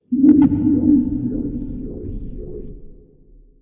山洞中沉睡的怪物低吼
描述：沉睡中的怪物在山洞中的声音效果。 高度有效地记录一个人的打鼾音调转移和大量的混响。
标签： 洞穴 电影 睡眠 恐怖 怪物 可怕的 影响
声道立体声